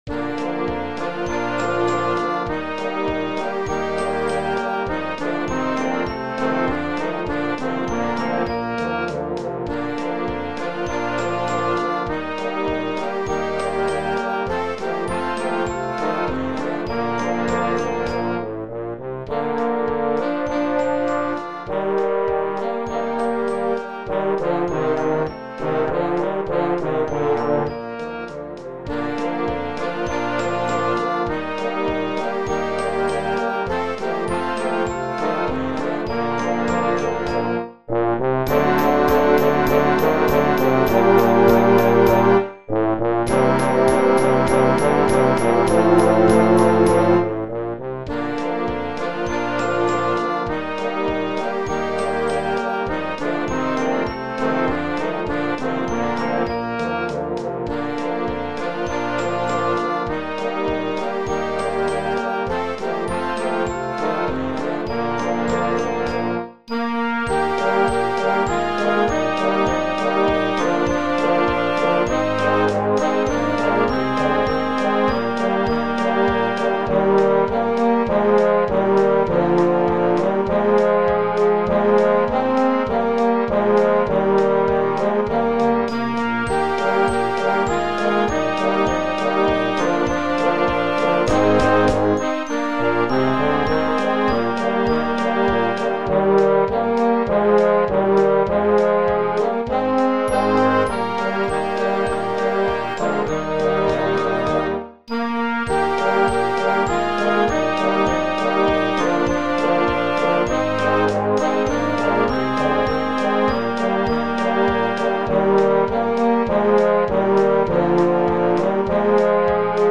Gattung: Polka für Jugendblasorchester
Besetzung: Blasorchester